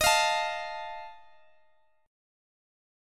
Listen to D#sus2b5 strummed